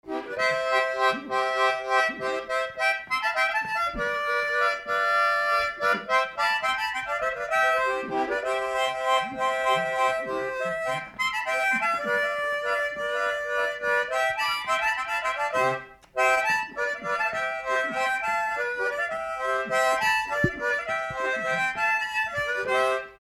Valse
danse : valse
circonstance : bal, dancerie
Pièce musicale inédite